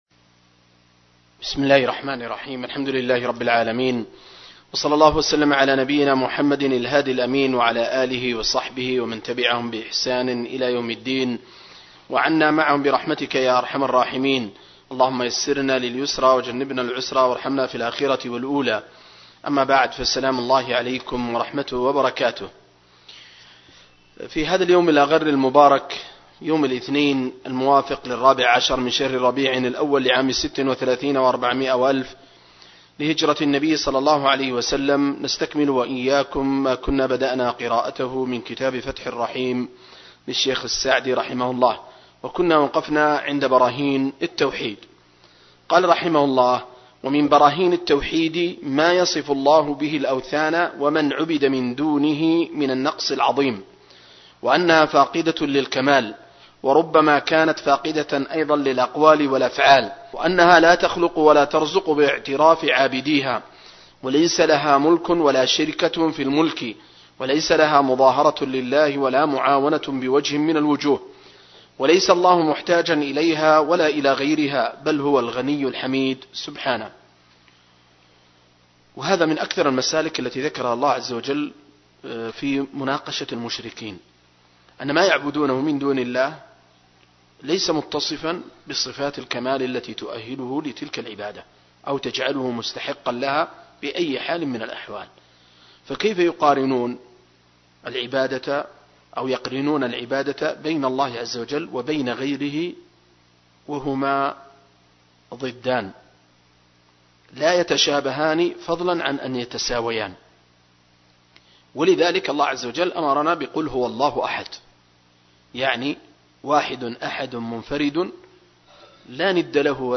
بقراءة وتعليق
دورة علمية في قاعة الدروس والمحاضرات